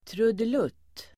Ladda ner uttalet
trudelutt substantiv (vardagligt), melodious phrase [informal]Uttal: [trudel'ut:] Böjningar: trudelutten, trudelutterDefinition: enkel och glad melodi